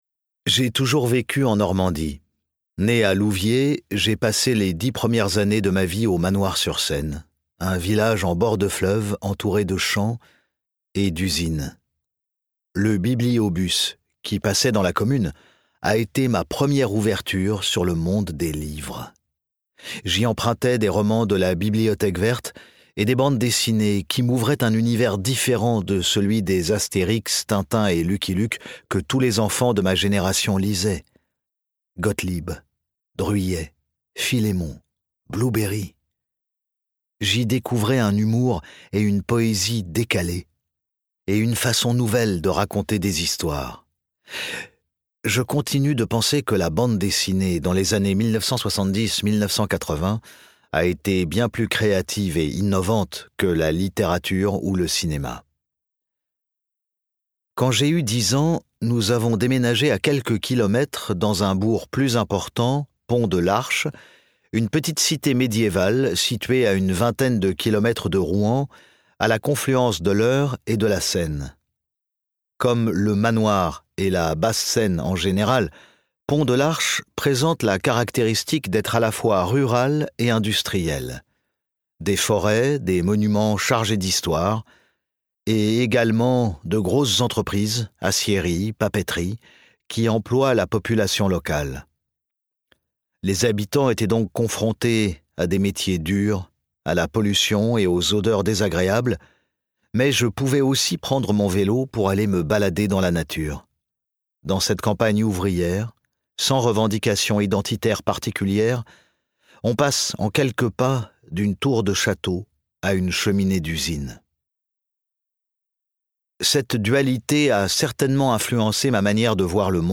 Le maître du suspense dévoile ses secrets d'écriture, à travers la voix enveloppante